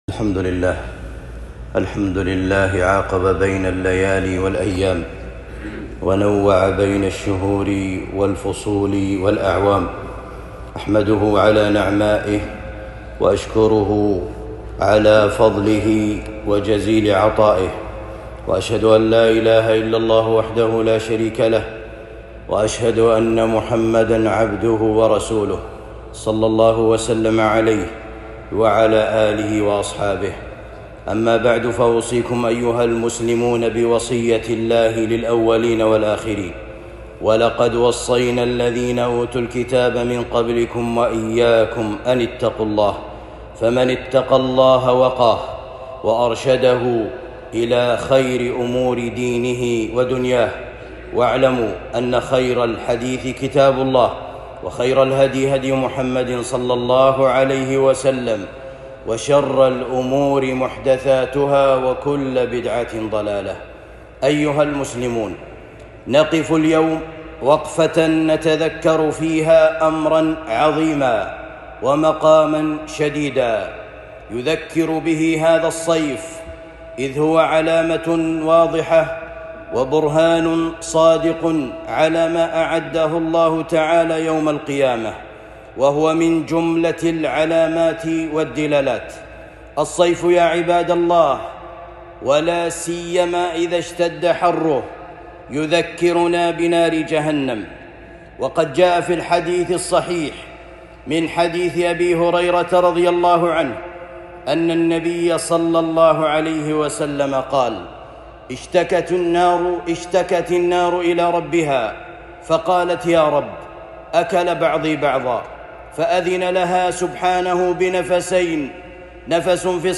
خطبة جمعة (نَحْنُ جَعَلْنَاهَا تَذْكِرَةً )